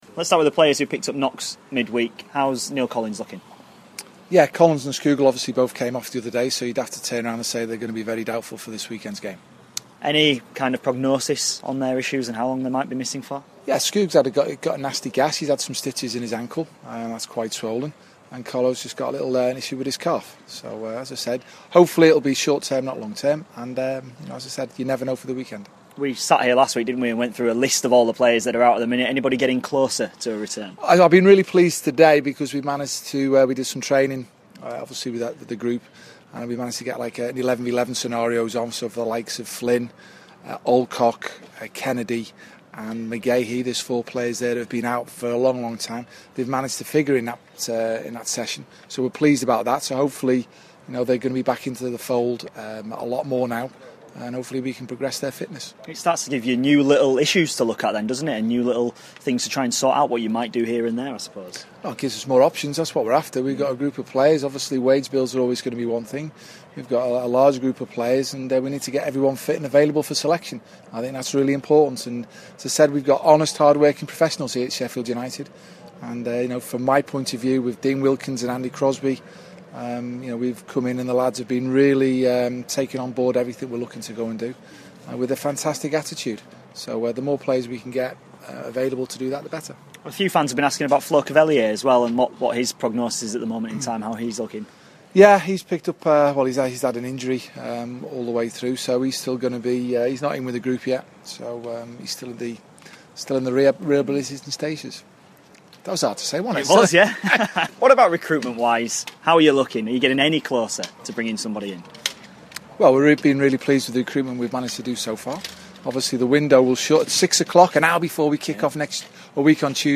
INTERVIEW: Sheffield United manager Nigel Adkins ahead of the Blades game with Blackpool